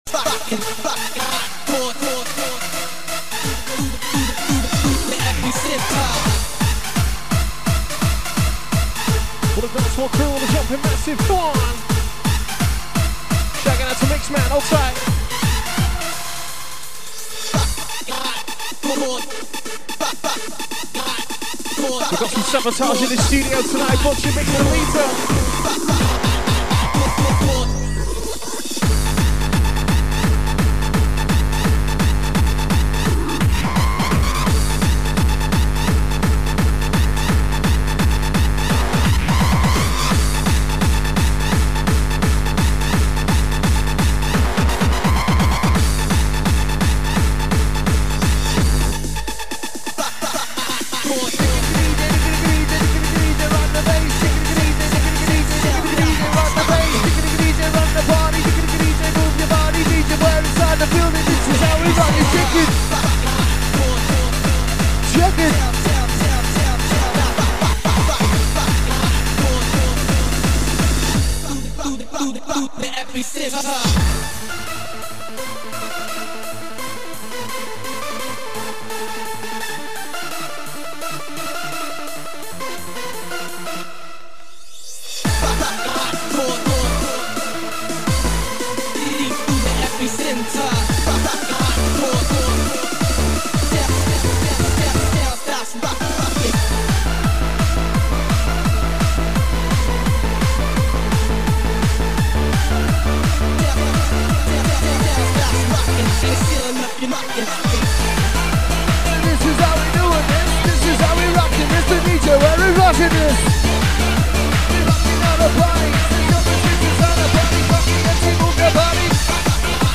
Phatt Hardcore Beats
Rave Hardcore Vocals